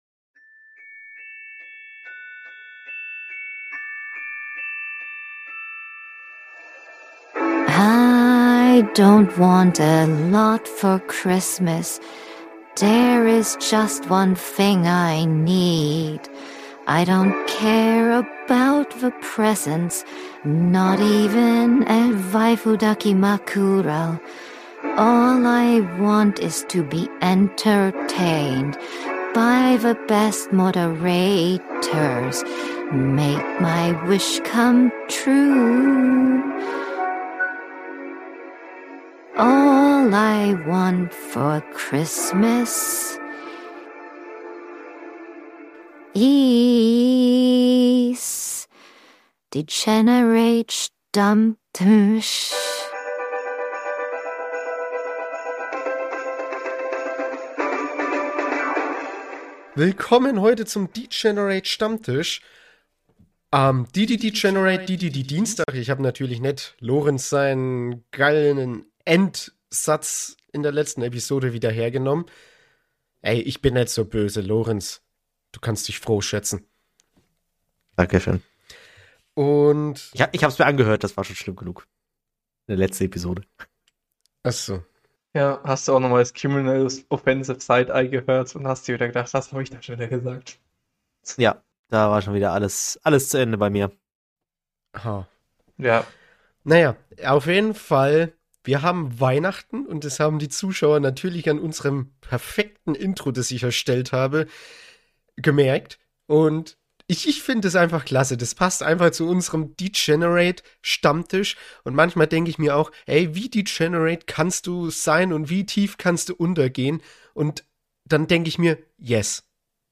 In Episode 23 starten wir mit einem super weihnachtlichen Intro, das mal wieder unsere Degenerateheit auf die Spitze treibt – also seid vorbereitet!